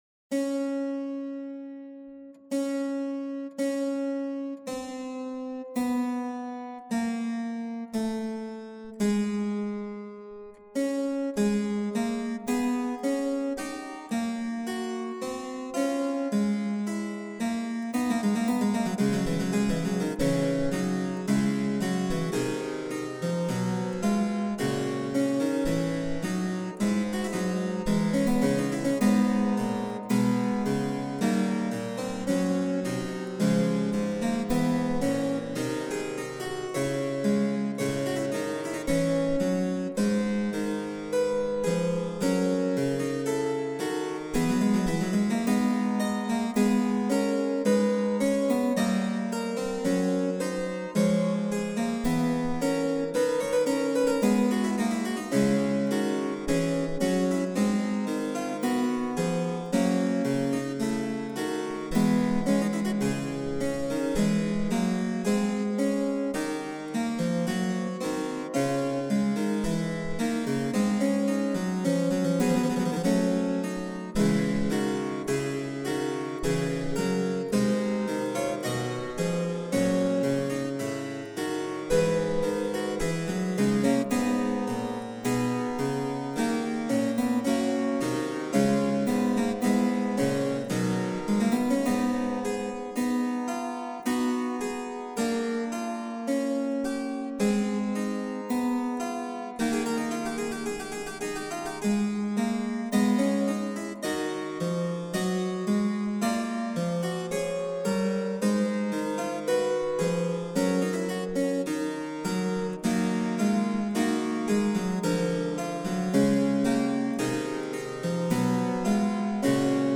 Departament de M�sica Antiga de l'ESMUC, Escola Superior de M�sica de Catalunya